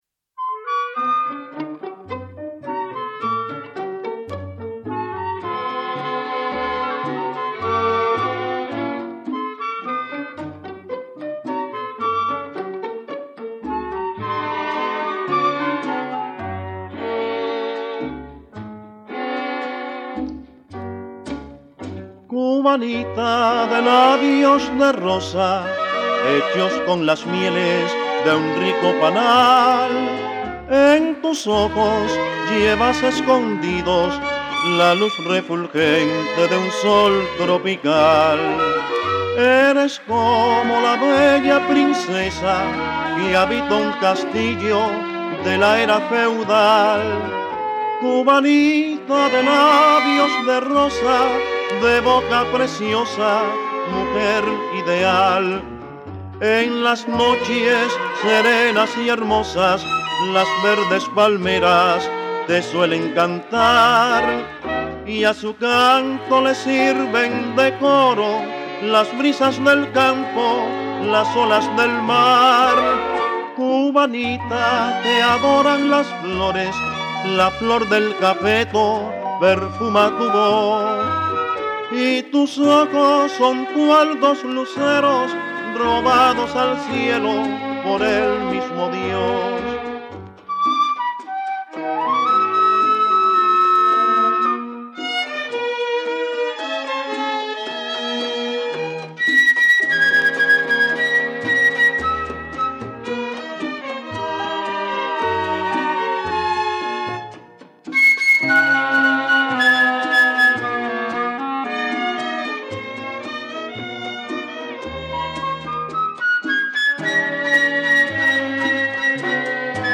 Долгоиграющая граммофонная пластинка.